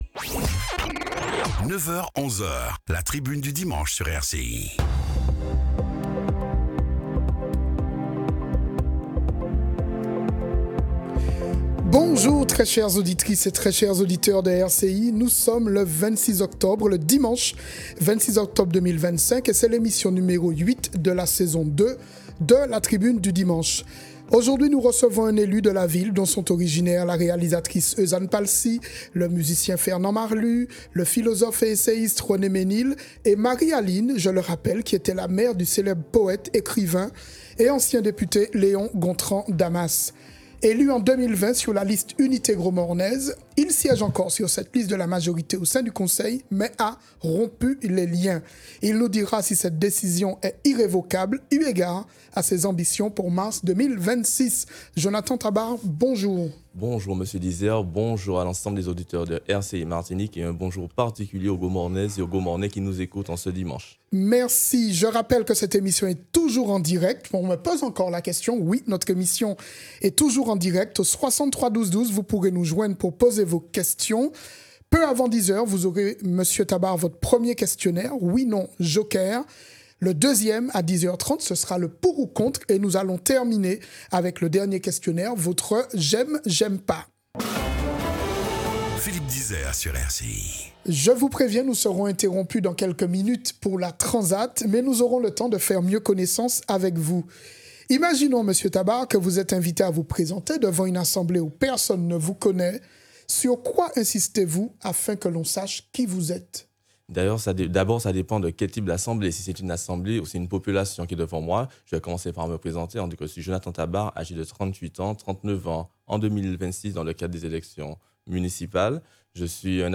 Détails de l'interview